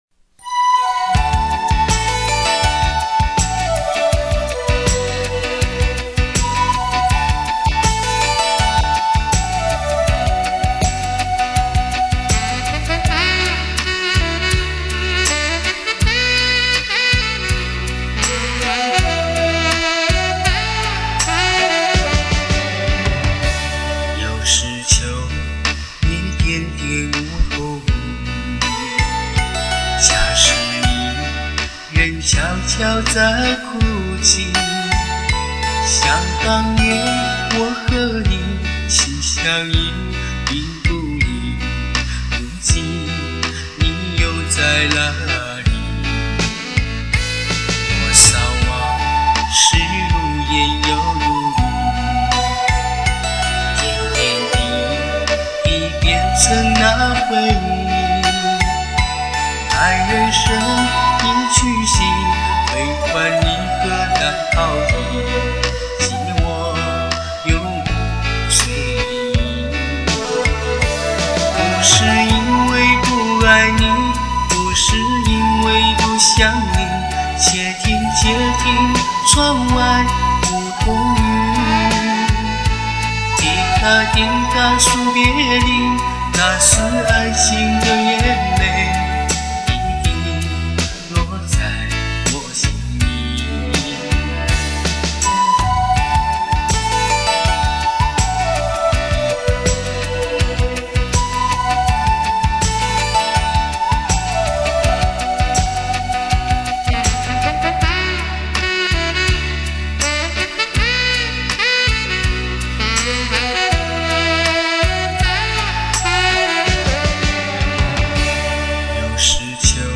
歌曲描写的是对爱人绵延不绝的相思之苦，优美动听的旋律表达了对爱人的情深意长。